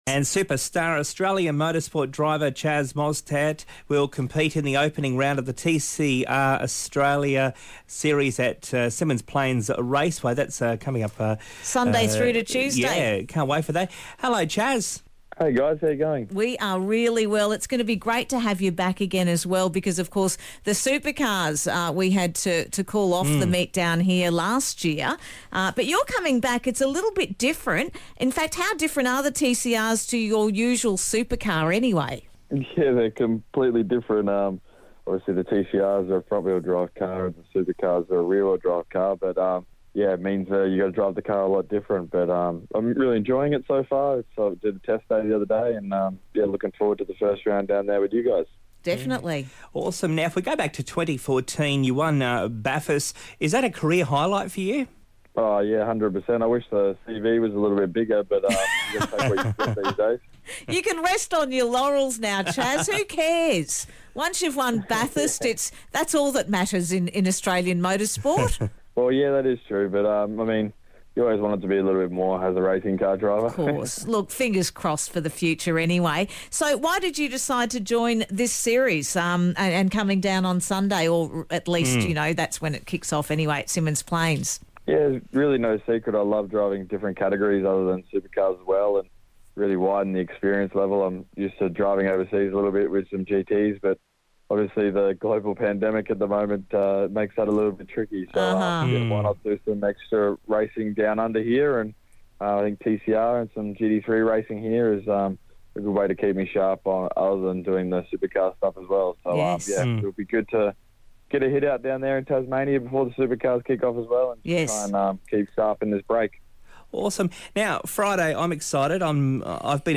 Chaz Mostert is taking part in the Boost Mobile Race Tasmania event this coming weekend - we had a chat with Chaz today...